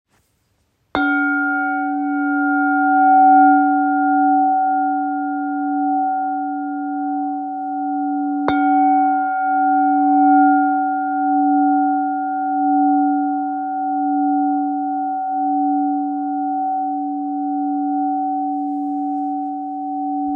Křišťálový kalich Duch JEDNOROŽCE - tón D, 2. sakrální čakra
432 Hz
Kategorie: Zpívající křišťálové mísy